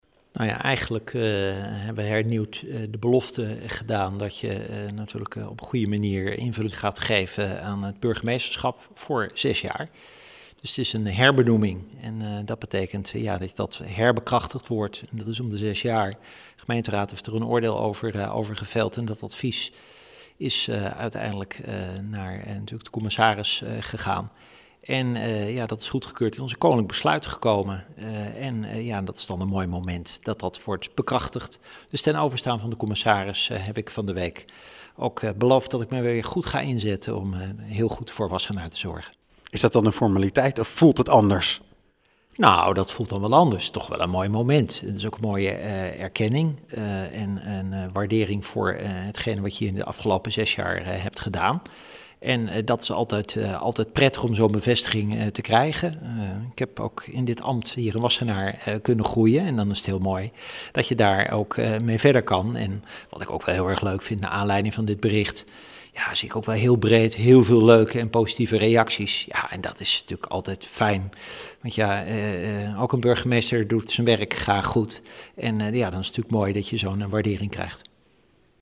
in gesprek met burgemeester Leendert de Lange over zijn verlenging.